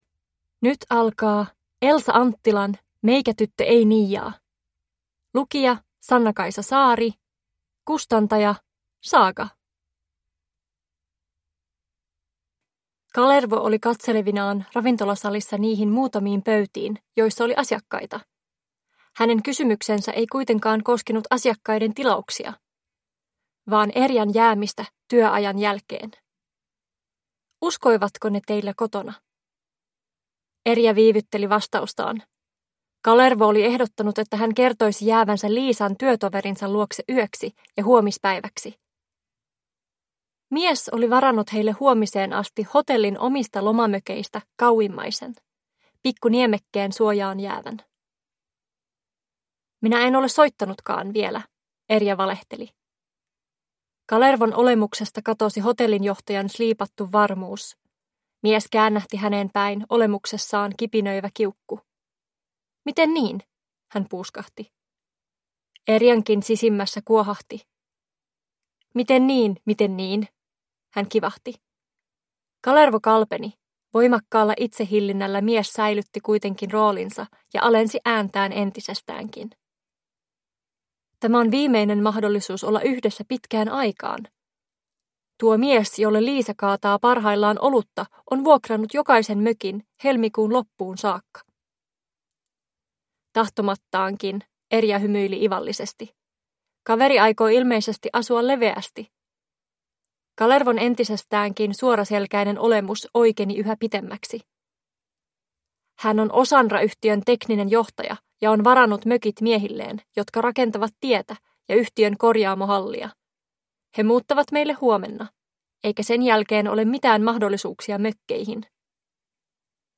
Meikätyttö ei niiaa (ljudbok) av Elsa Anttila